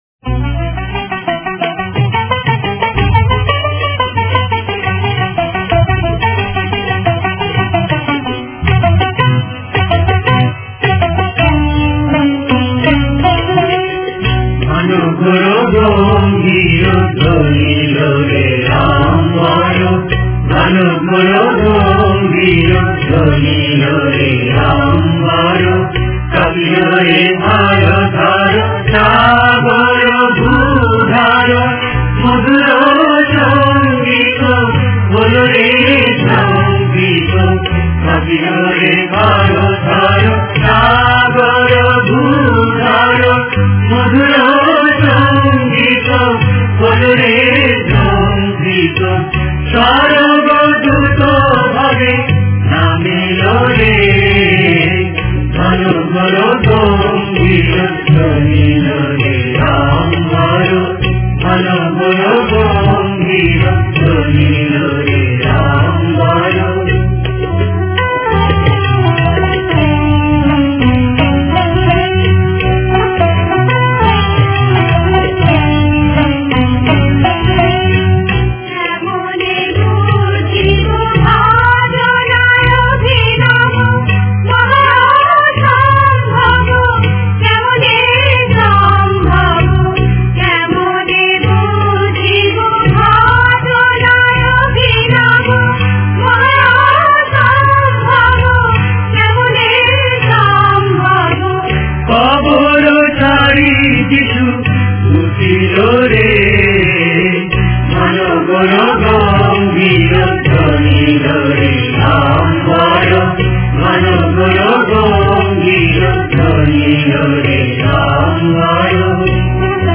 Directory Listing of mp3files/Bengali/Devotional Hymns/Good Friday/ (Bengali Archive)